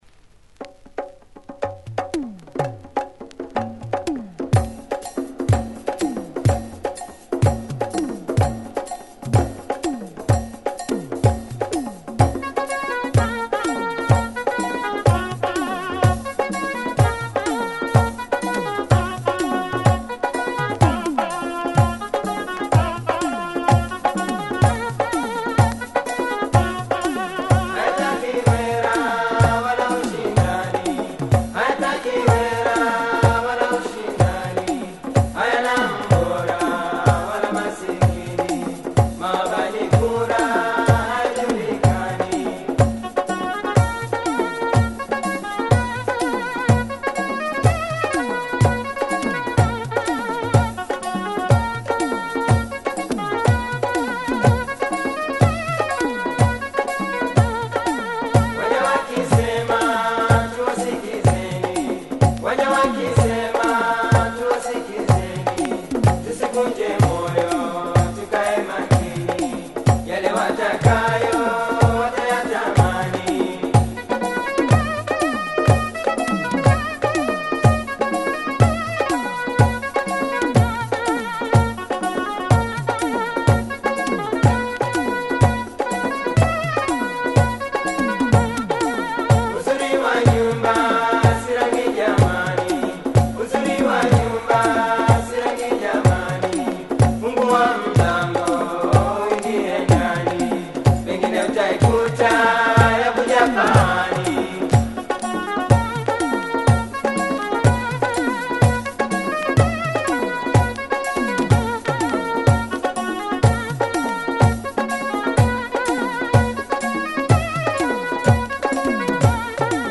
Great percussion heavy synth groover